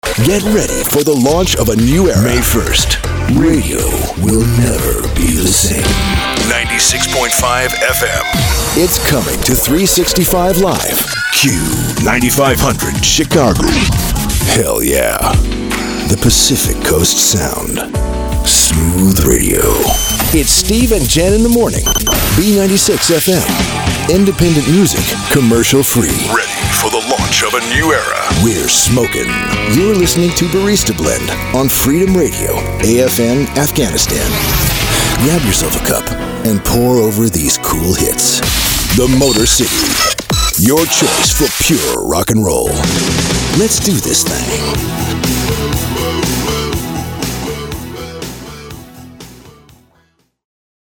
Imaging
His rugged baritone is well-traveled, trustworthy, believable and altogether gripping. It can be intimate and inviting, or intense and commanding.